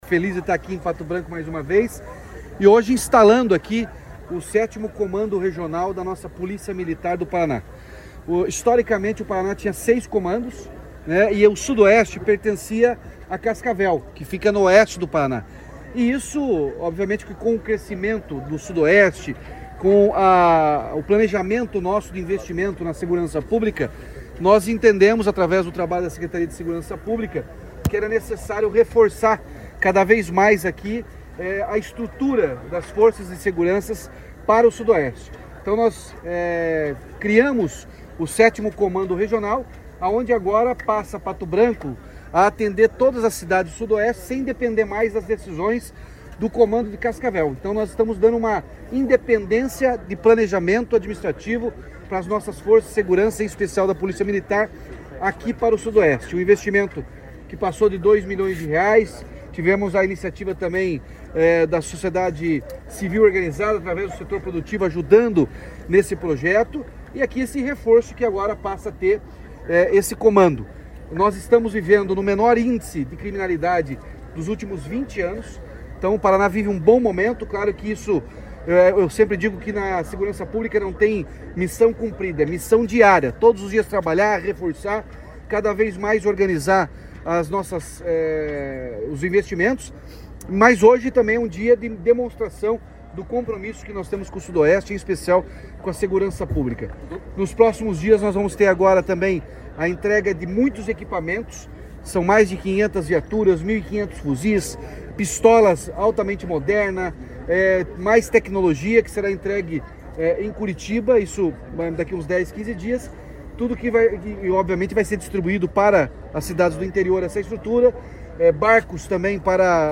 Sonora do governador Ratinho Junior sobre a sede do Comando Regional da PMPR em Pato Branco